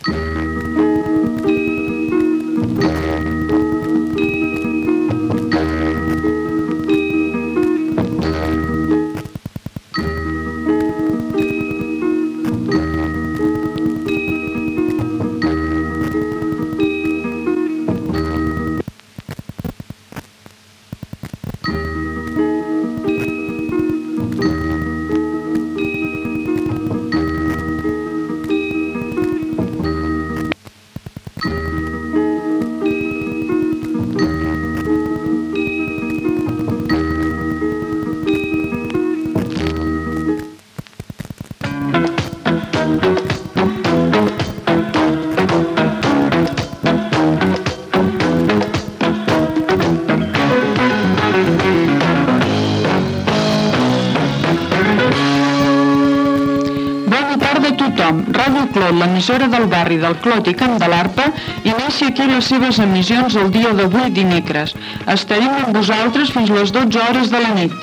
Sintonia i inici de l'emissió